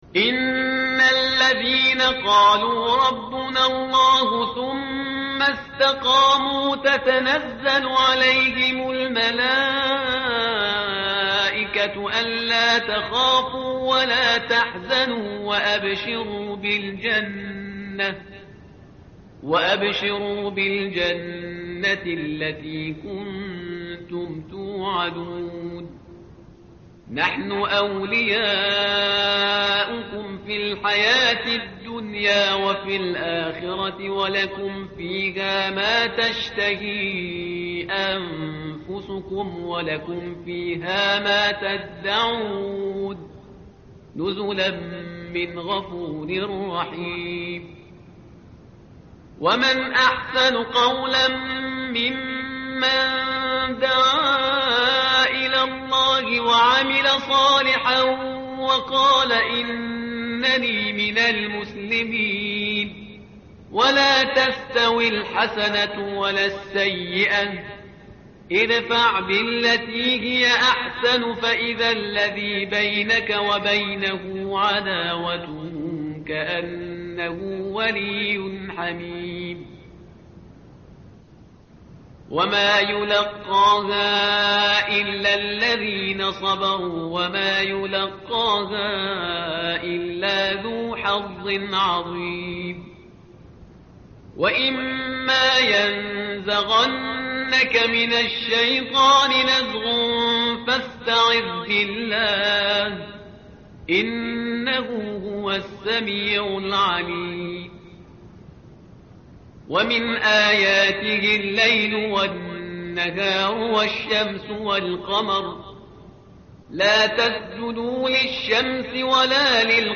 متن قرآن همراه باتلاوت قرآن و ترجمه
tartil_parhizgar_page_480.mp3